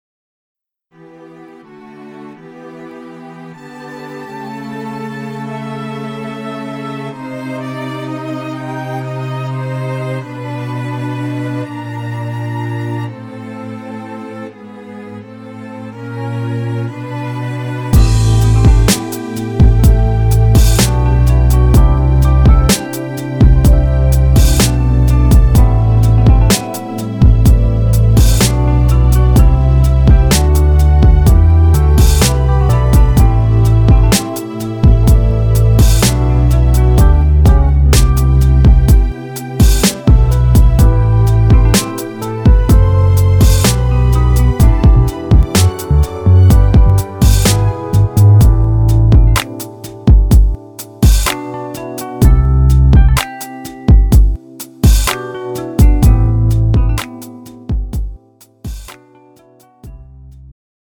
장르 pop 구분 Pro MR